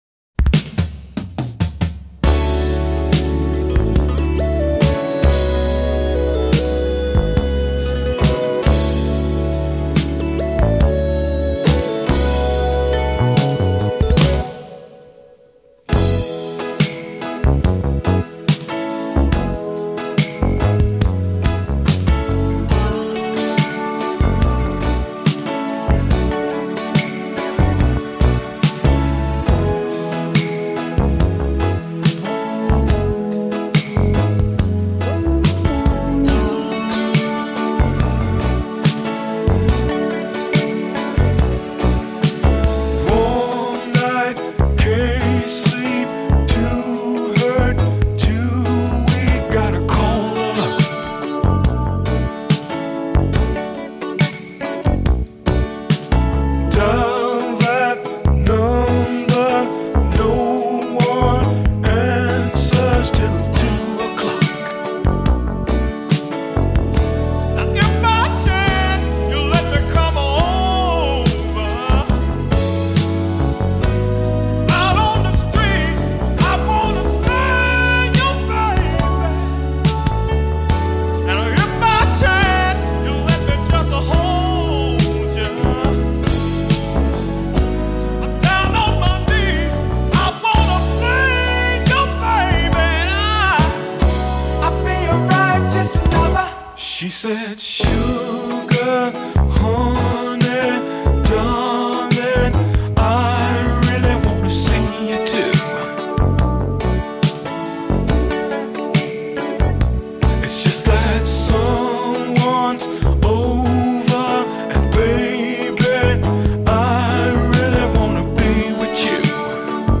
is a ballad that perfectly tells of one of love’s dilemmas